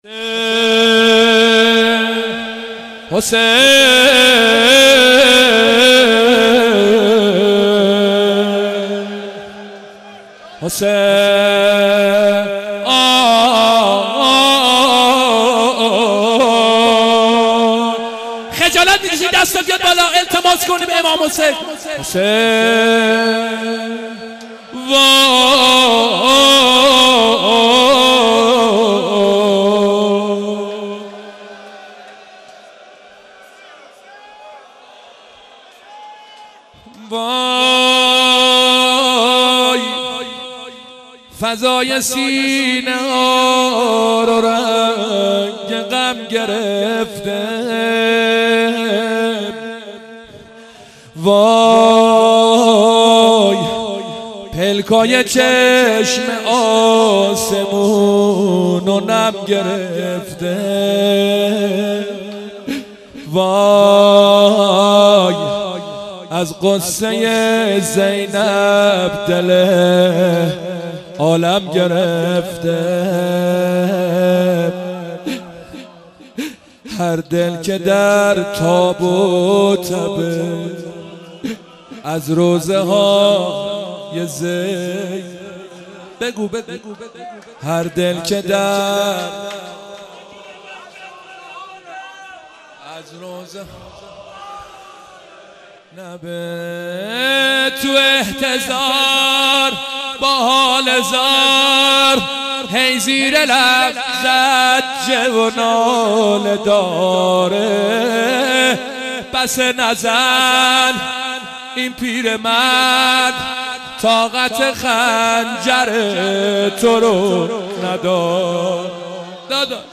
مناسبت : وفات حضرت زینب سلام‌الله‌علیها
قالب : روضه